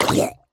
minecraft / sounds / mob / drowned / hurt2.ogg
hurt2.ogg